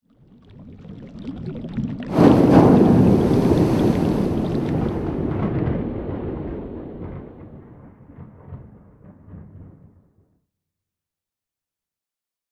cauldron-done.ogg